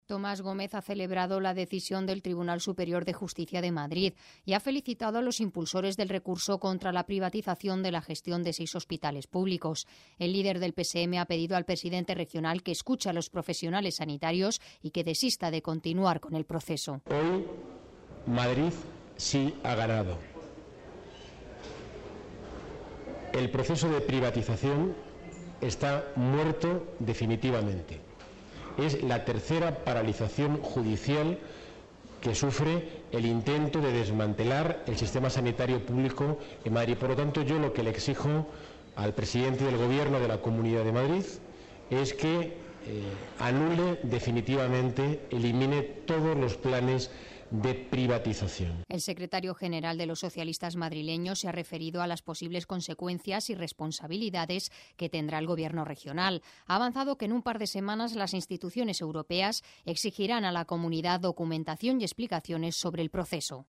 Tomás Gómez ha valorado así en los pasillos del Senado el auto de la Sección tercera de la Sala de lo Contencioso del Tribunal Superior de Justicia de Madrid (TSJM) que ha decretado la suspensión cautelar de la resolución de la Comunidad de Madrid por la que se hizo pública la convocatoria para externalizar la gestión de seis hospitales públicos.